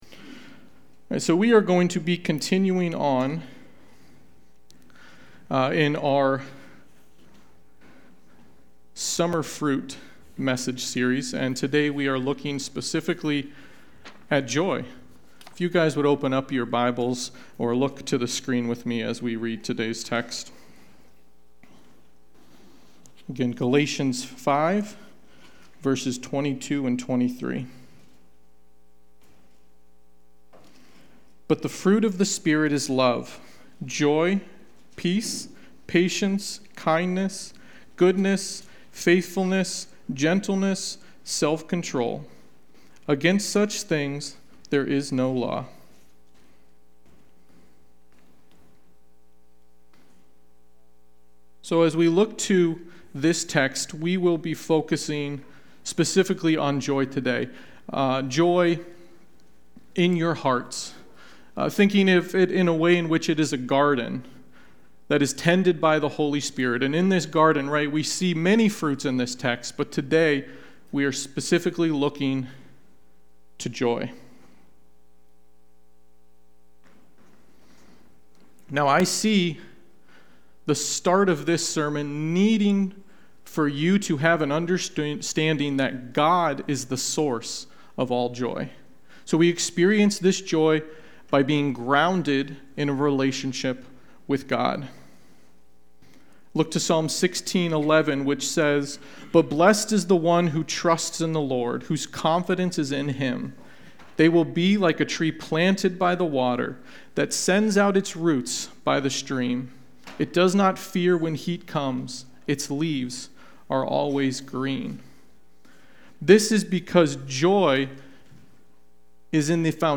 Sunday-Worship-main-62324.mp3